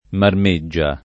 marmeggia [ marm %JJ a ]